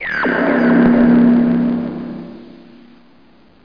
TELEPORT.mp3